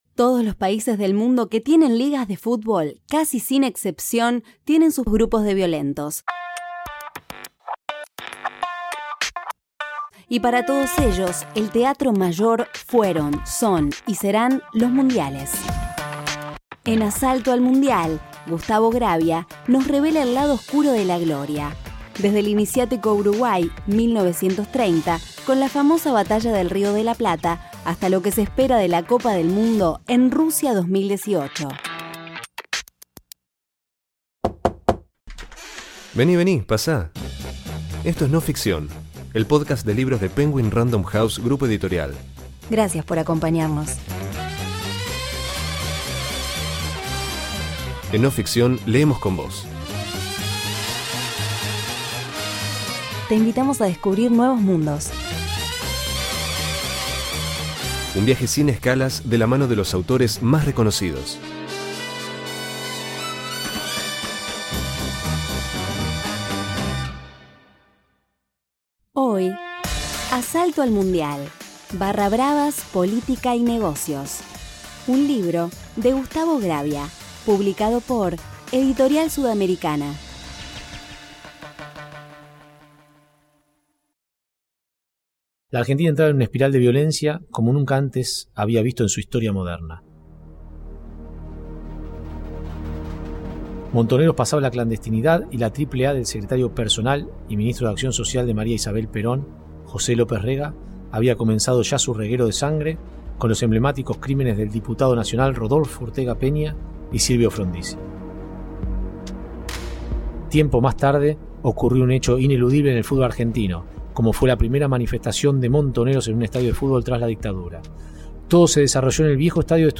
Este episodio incluye música y FX